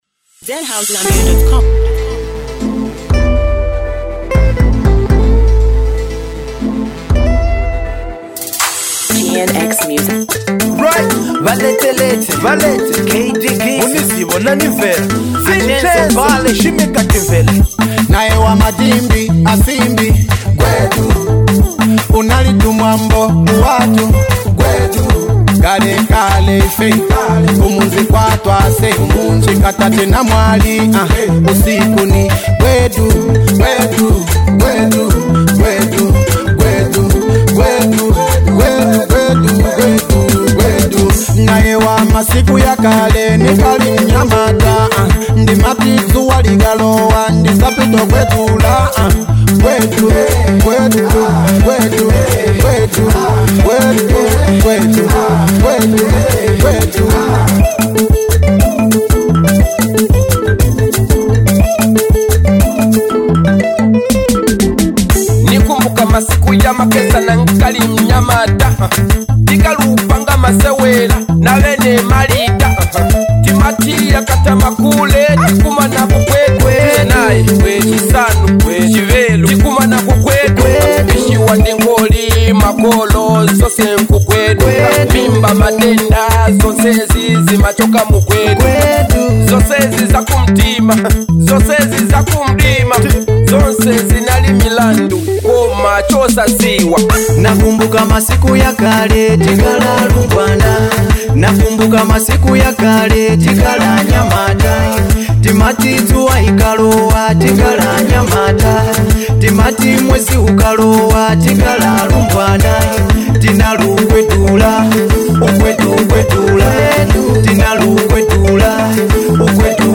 a high-energy banger